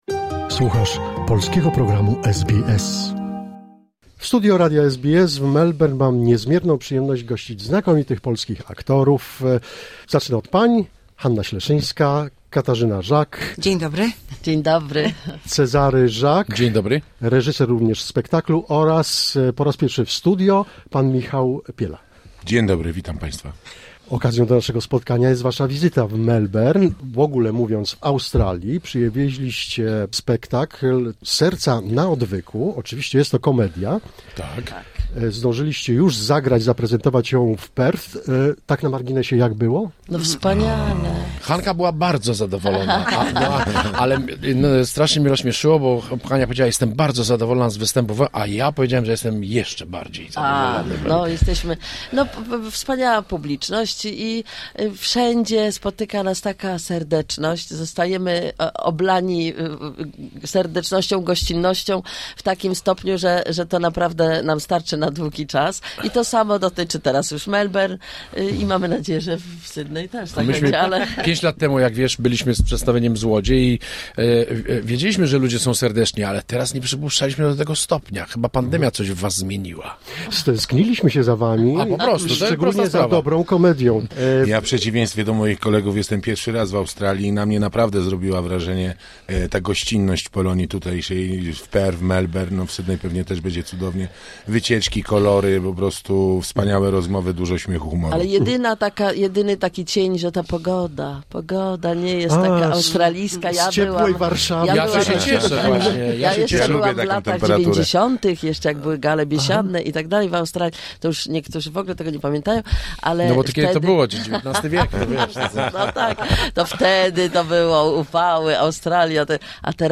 Znakomici aktorzy komediowi: Cezary Żak, Katarzyna Żak, Hanna Śleszyńska, Michał Piela odwiedzili studio SBS w Melbourne. Aktorzy występują w Australii w komedii 'Serca na Odwyku'.
Hanna Śleszyńska, Michał Piela, Katarzyna Żak, Cezary Żak w Radiu SBS w Melbourne